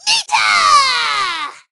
nita_start_vo_01.ogg